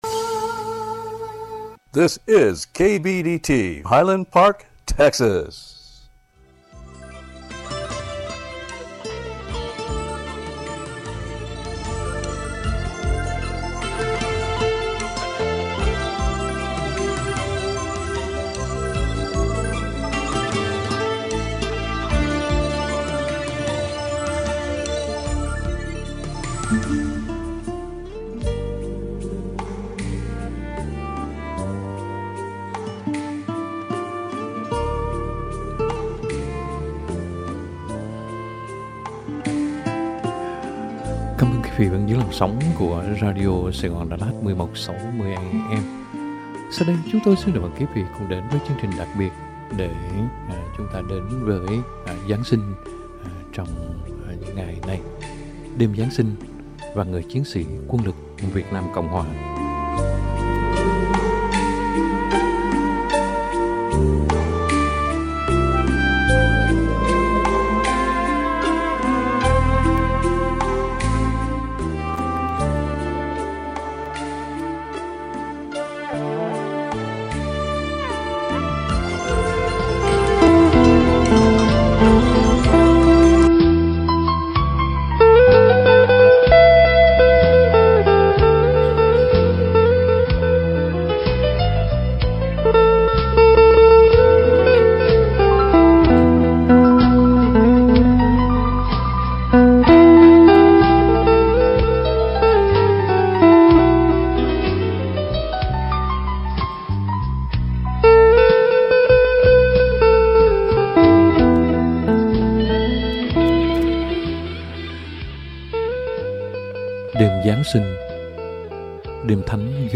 * Dien doc